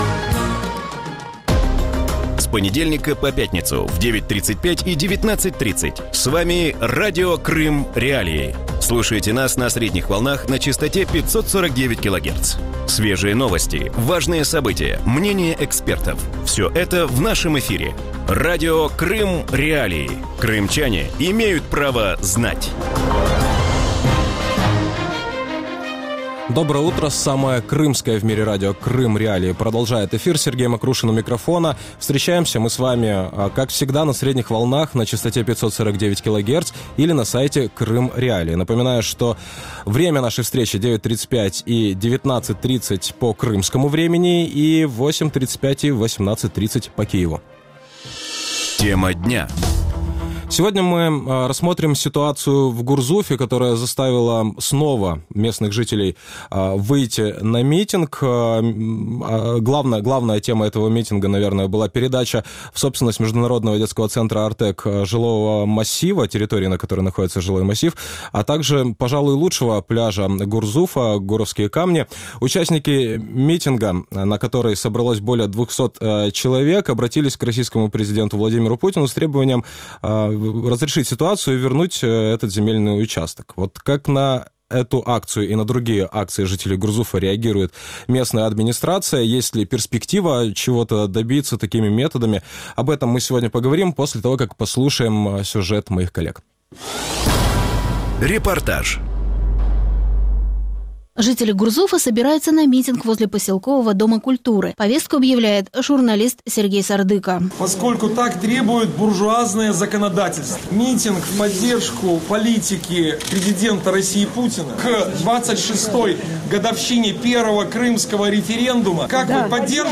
У ранковому ефірі Радіо Крим.Реалії говорять про протест в Гурзуфі. Місцеві жителі вийшли на мітинг проти передачі житлового масиву, а також пляжу «Гурівські камені» міжнародному дитячому центру «Артек». Учасники акції, а це понад двісті осіб, звернулися до президента Росії з вимогою повернути земельну ділянку в муніципальну власність.